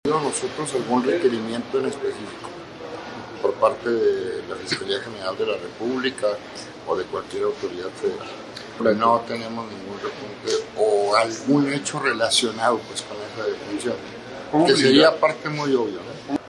AUDIO: CÉSAR JÁUREGUI MORENO, FISCAL GENERAL DEL ESTADO (FGE)